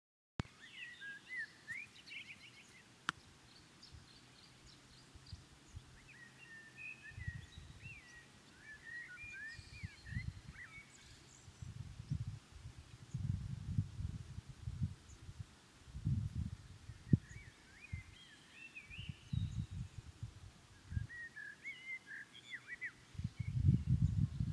In May 2020 a 8500 sq.m property became available in Catela, a very small and isolated village of the Garafia municipality, located around 1200m altitude in a spot very easily accessible from the LP-1 road (km 67) which offers very dark skies in a natural beauty scenery surrounded by Canarian pines, chirping birds
Birds-chirping-Catela.m4a